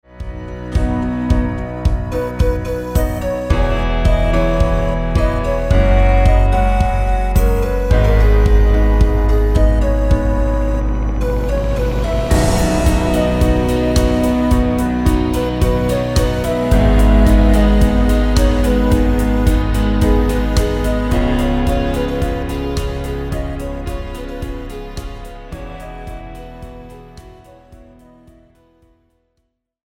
Žánr: Pop
BPM: 109
Key: G#m
MP3 ukázka